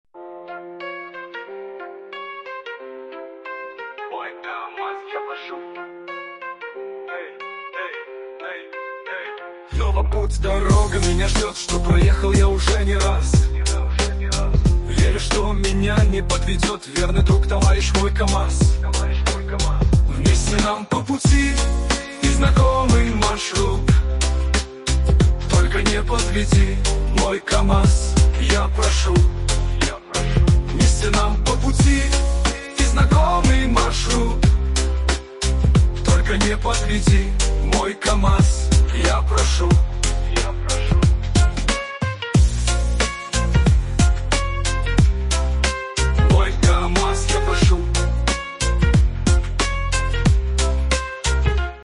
Стиль — шансон.